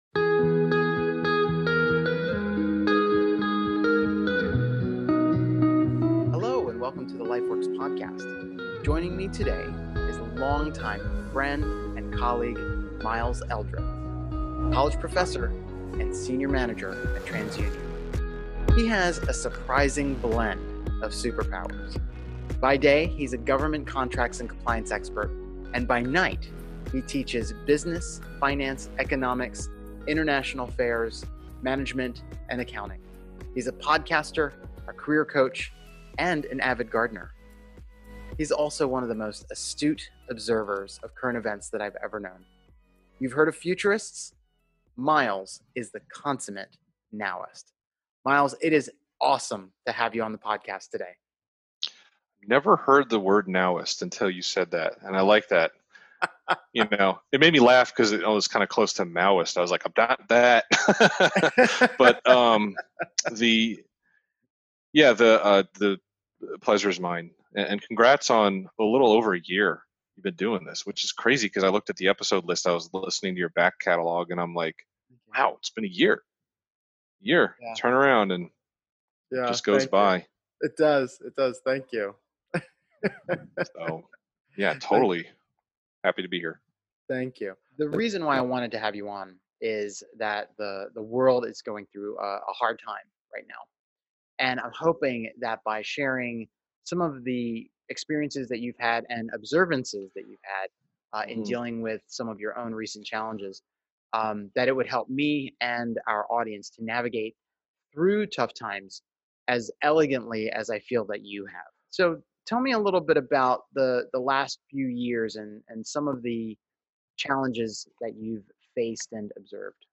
Episode 032: How to Get Through Hard Times – An Interview